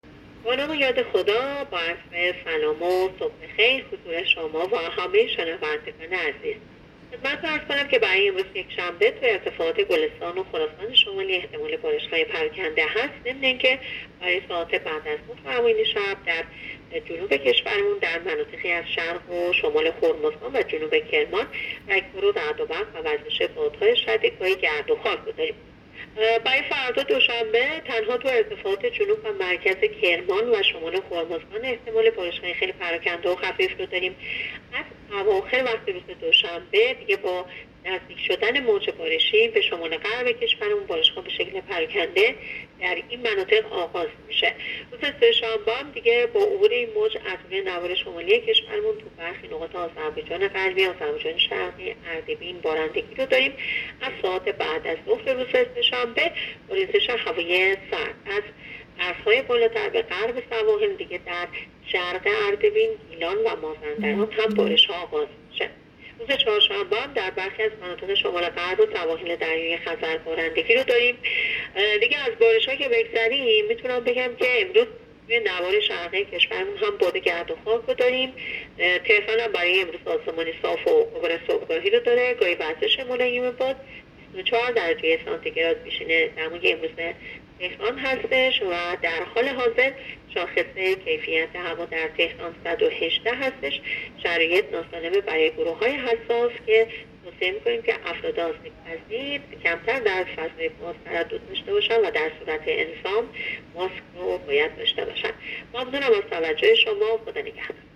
گزارش رادیو اینترنتی پایگاه‌ خبری از آخرین وضعیت آب‌وهوای ۲۷ مهر؛